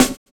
Snare set 2 009.wav